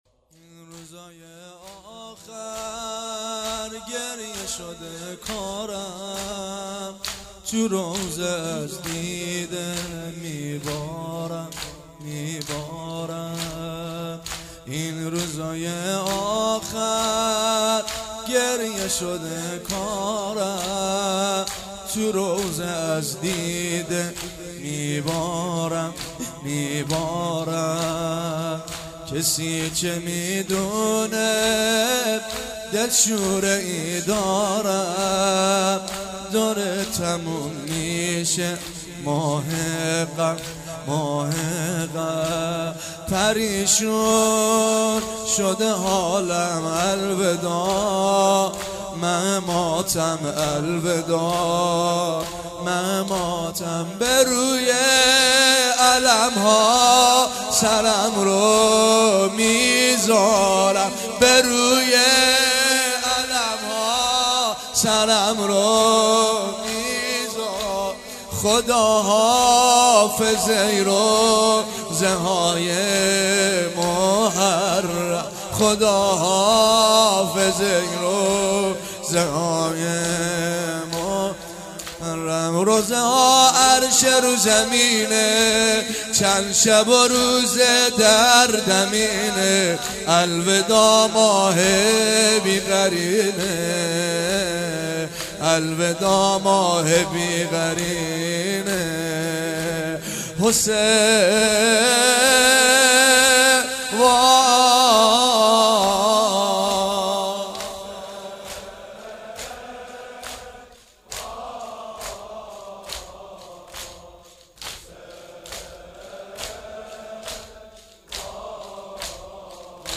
چهاراه شیرودی حسینیه حضرت زینب(س)